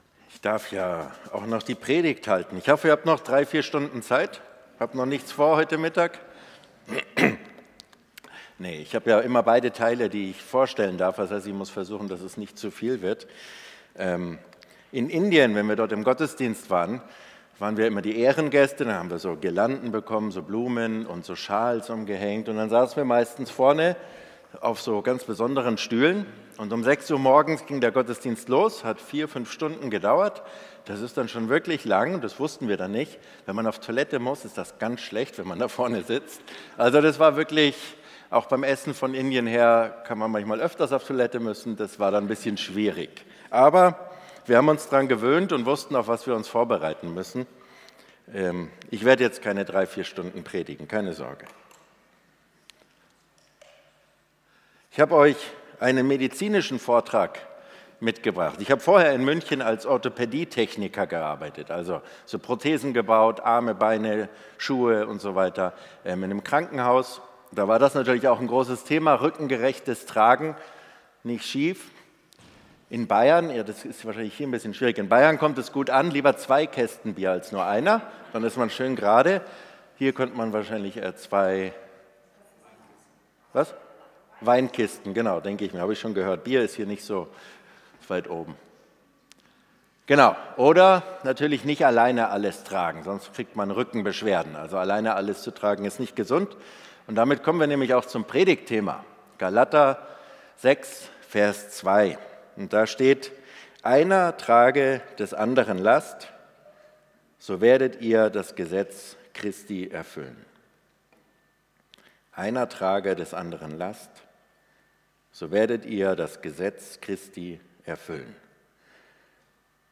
Predigt vom 16.11.2025